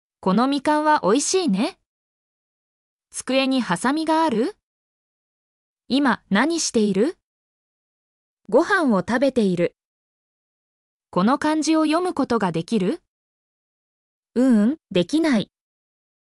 mp3-output-ttsfreedotcom-79_xGNW0AVE.mp3